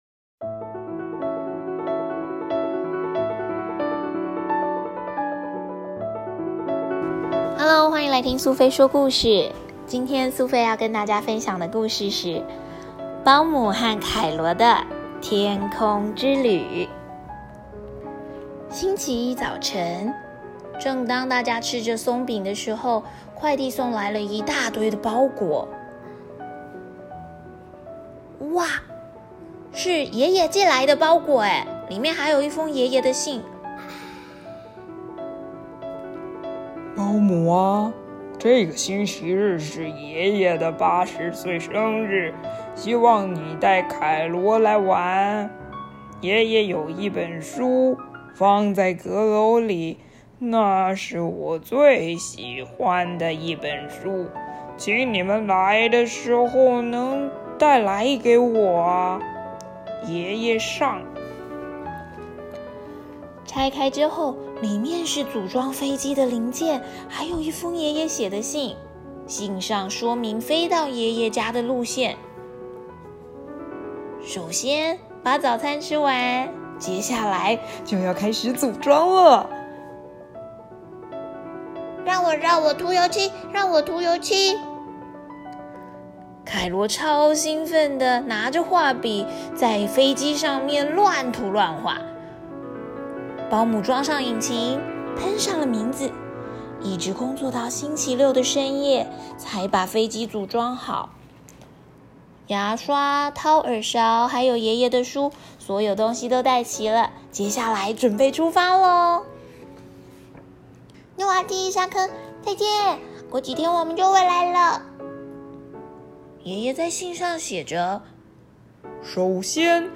1. 感謝你花時間錄製這麼棒的有聲故事，搭配繪本看小孩很喜歡～